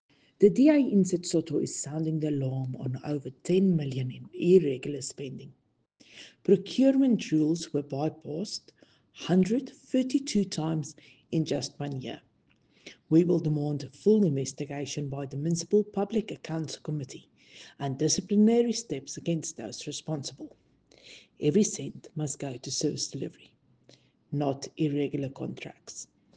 English and Afrikaans soundbites by Cllr Riëtte Dell and